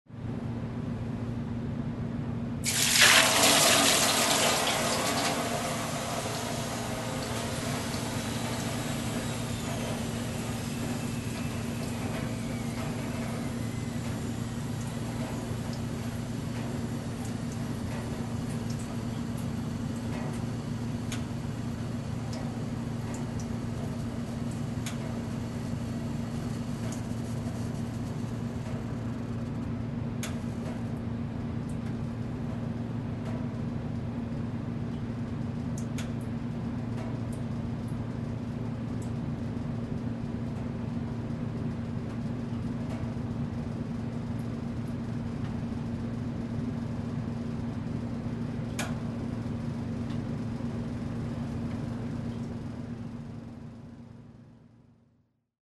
Звуки бани
Погрузитесь в атмосферу настоящей русской бани с подборкой звуков: треск дров, плеск воды на каменку, шипение пара.